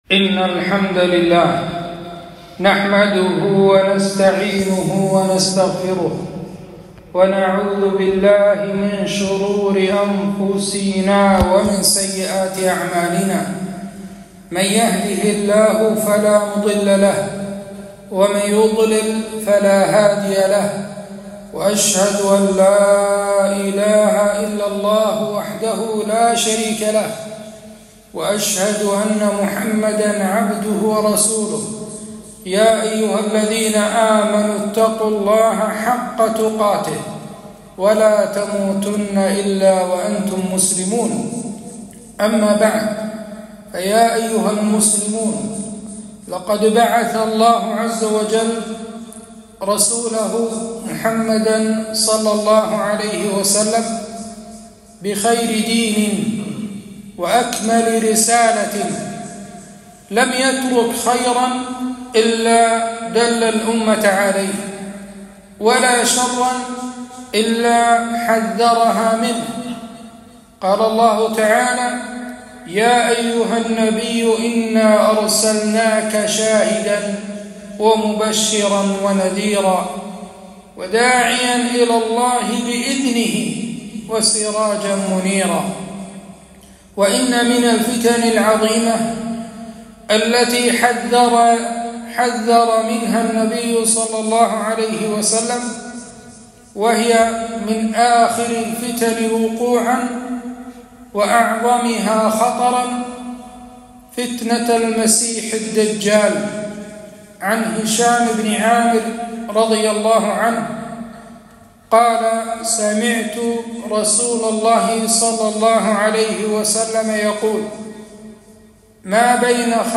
خطبة - التحذير من فتنة الدجال وبيان صفته